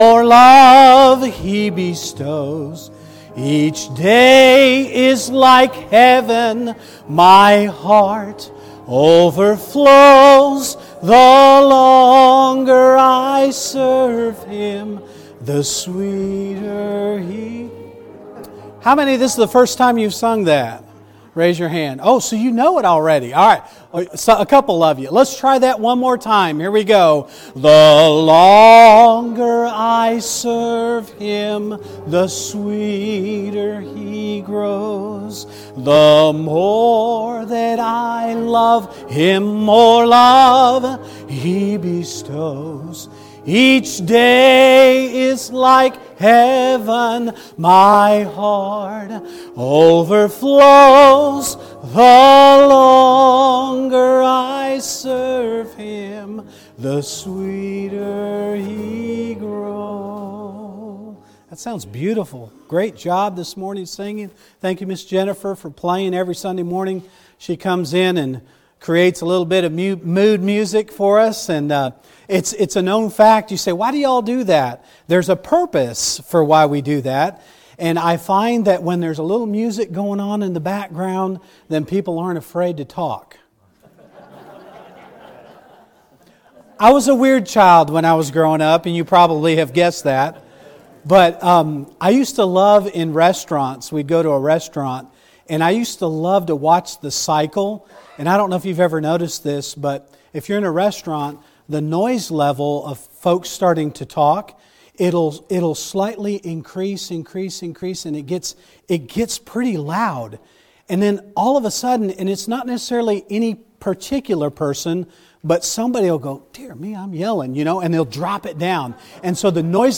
02-05-23 Sunday School Lesson | Buffalo Ridge Baptist Church